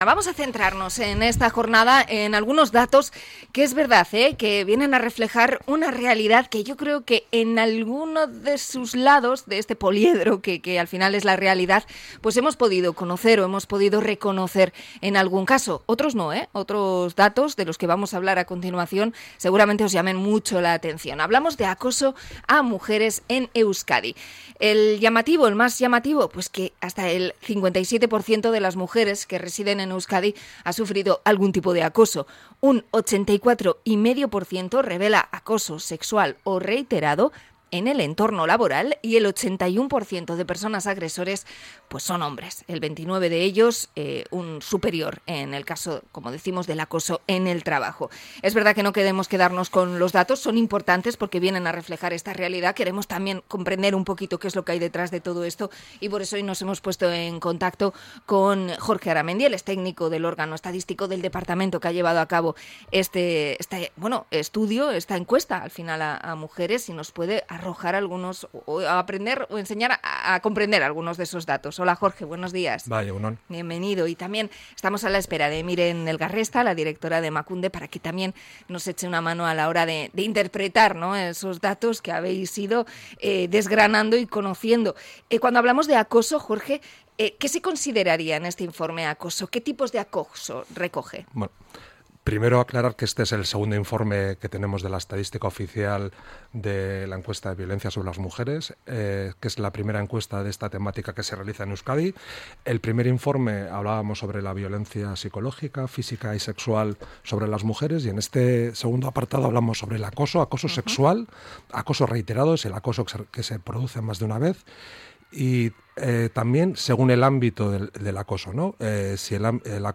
Entrevista sobre el informe sobre acoso a mujeres en Euskadi